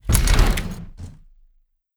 door sounds